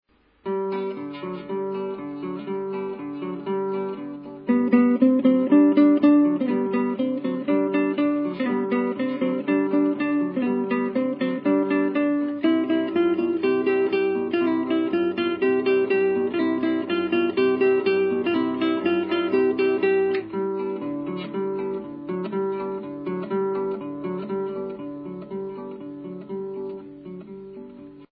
Ηχητική μπάντα παράστασης
κιθάρα